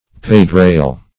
pedrail - definition of pedrail - synonyms, pronunciation, spelling from Free Dictionary
Pedrail \Ped"rail`\, n. [See Pedi-; Rail.] (Mach.)